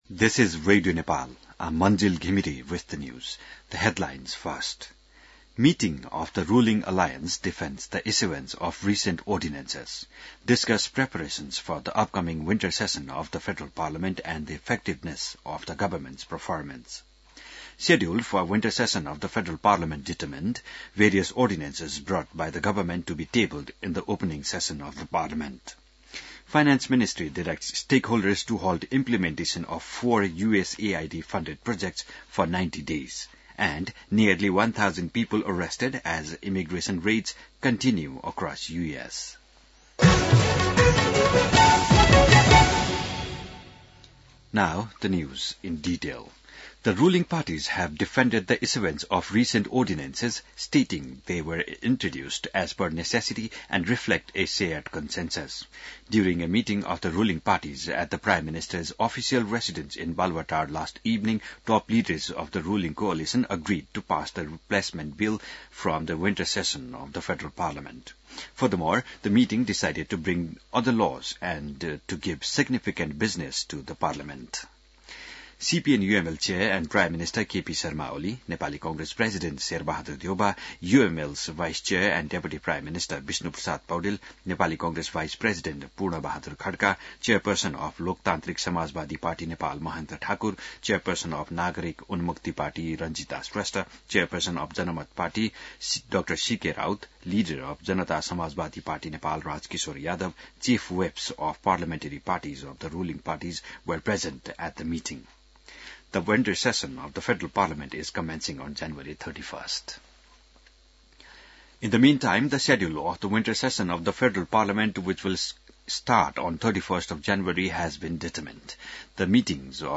बिहान ८ बजेको अङ्ग्रेजी समाचार : १६ माघ , २०८१